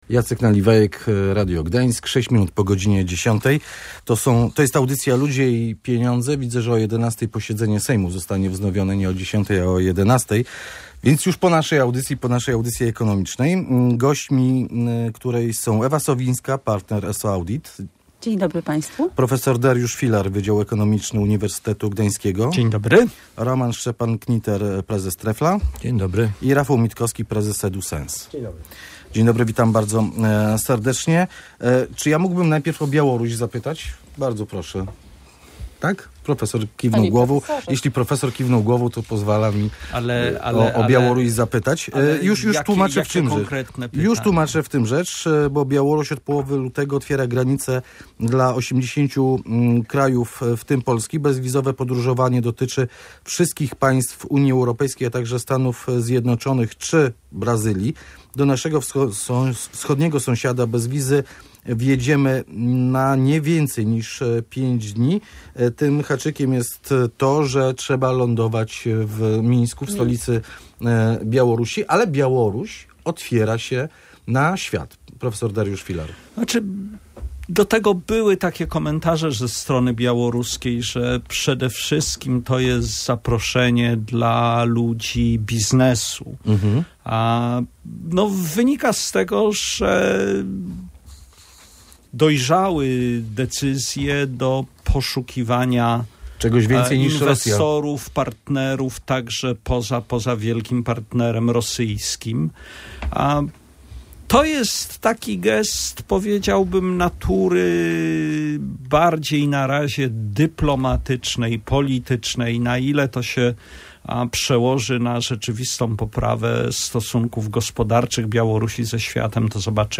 Wyjaśniali eksperci audycji Ludzie i Pieniądze.